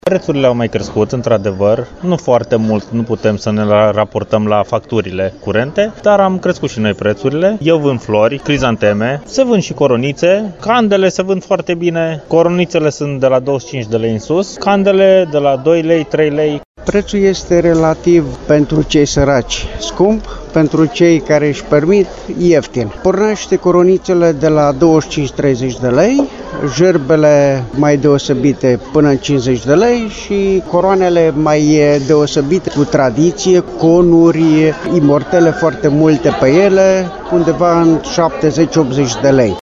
VOX-comercianti.mp3